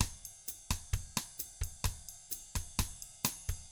129BOSSAF3-R.wav